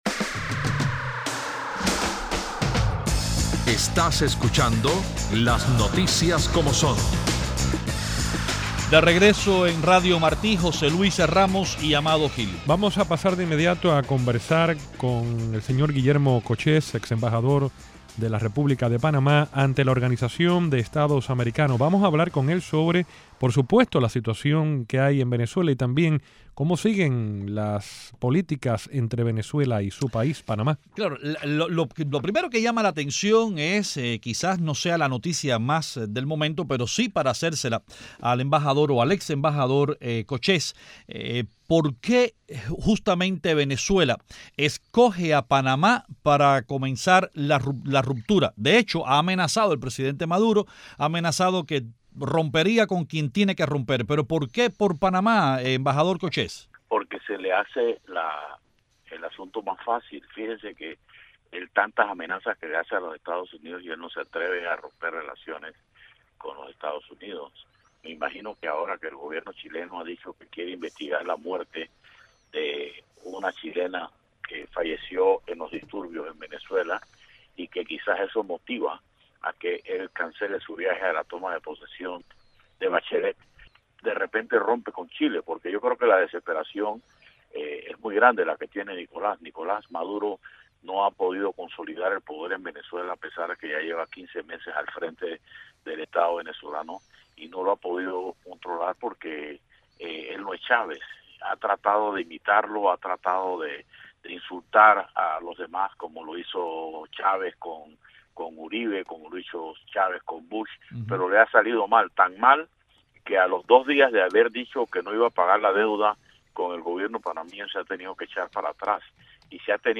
ambos desde La Habana, comentan las noticias más importantes del día.